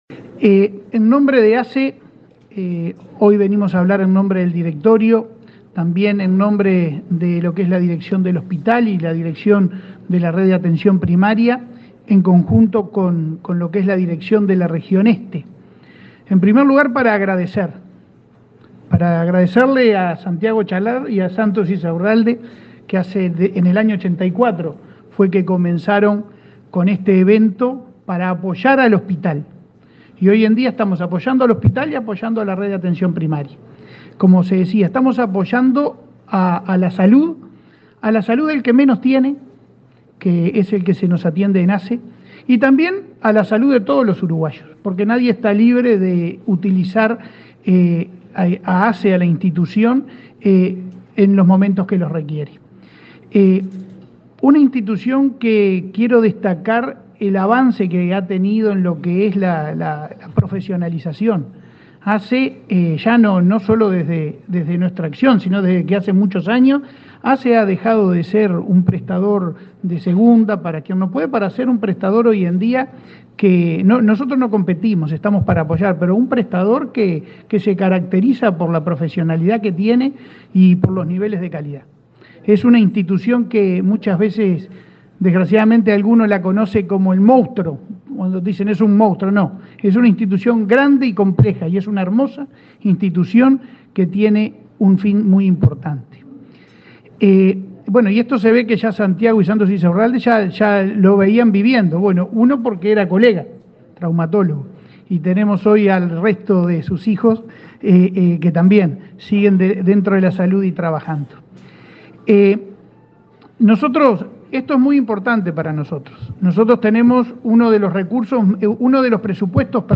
Palabra de autoridades en lanzamiento del festival Minas y Abril 12/04/2023 Compartir Facebook X Copiar enlace WhatsApp LinkedIn El presidente de la Administración de los Servicios de Salud del Estado (ASSE), Leonardo Cipriani; el ministro de Turismo, Tabaré Viera, y la vicepresidenta de la República Beatriz Argimón, fueron los oradores del acto de lanzamiento del festival Minas y Abril, este miércoles 12 en Montevideo.